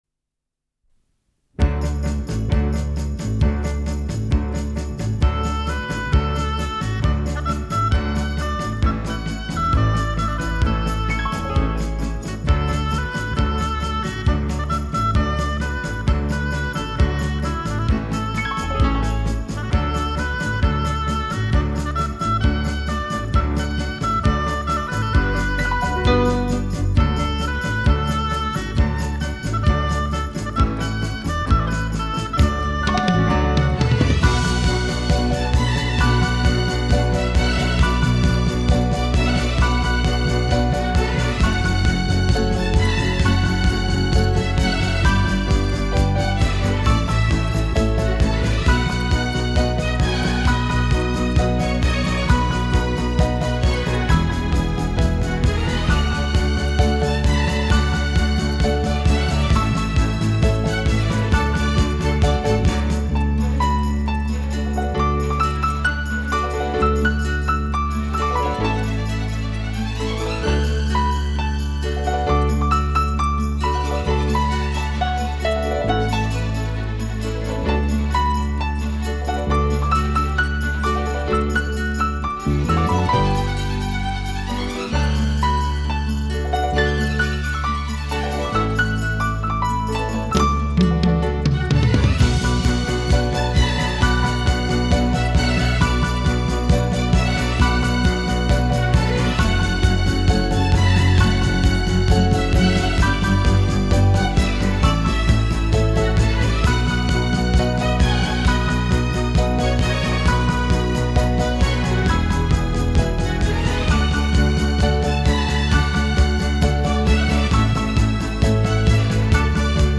均可，配以节奏鲜明的打击乐，主奏以民乐、西乐器为主，
形式多种多样，曲式异彩纷呈。